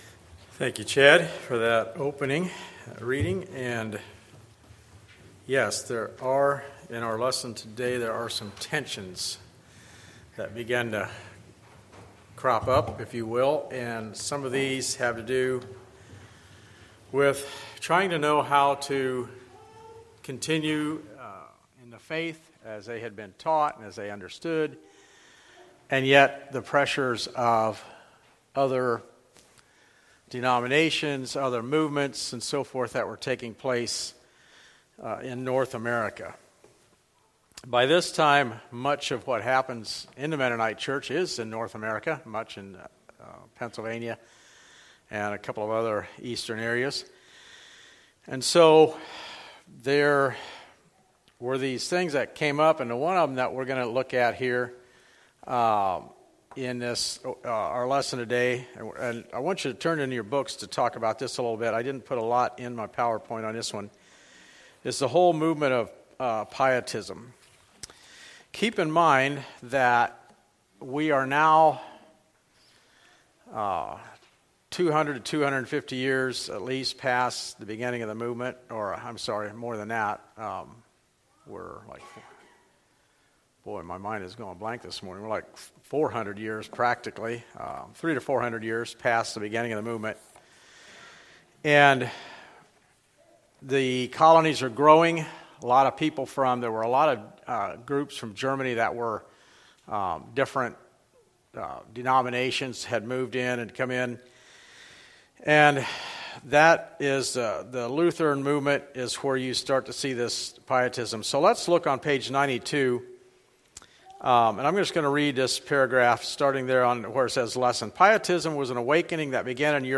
Like this sermon?